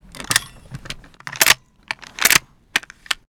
machinegun_reload_01.wav